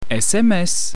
"SMS!" / Kiesel in der Aare (siehe auch hier) /